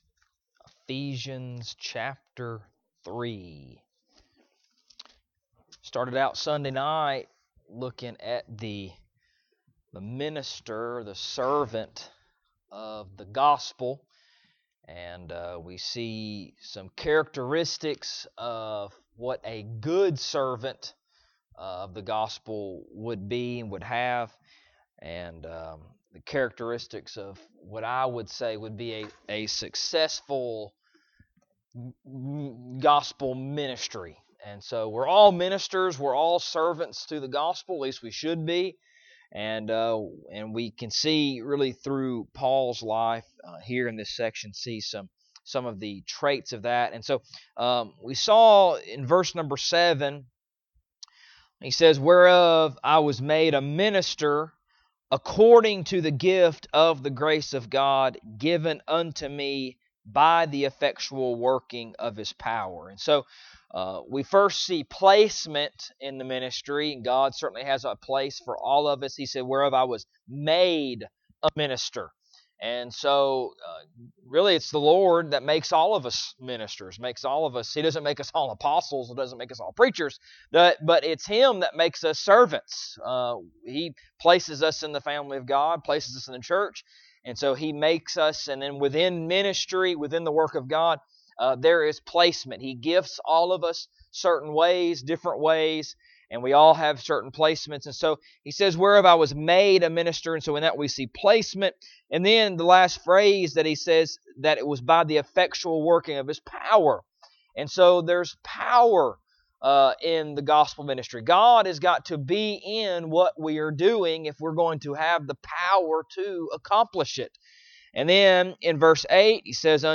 Ephesians Passage: Ephesians 3:11-13 Service Type: Wednesday Evening Topics